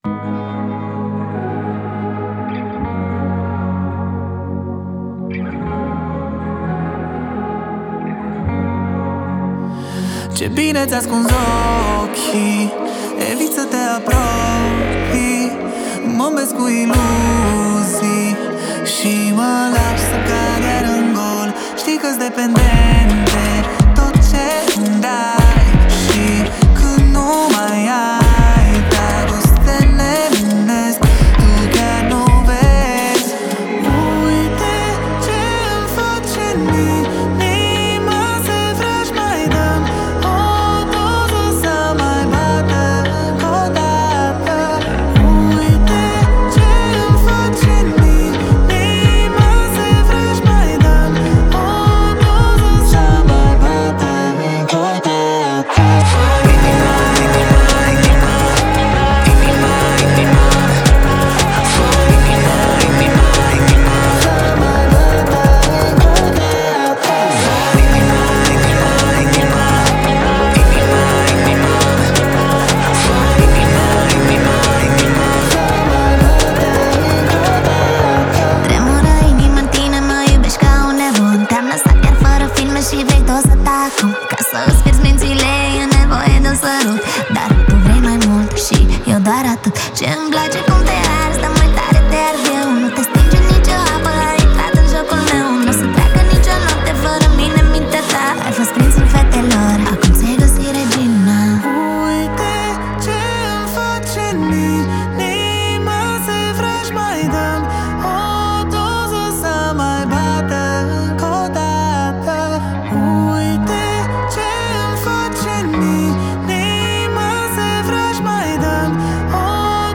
это завораживающая композиция в жанре инди-поп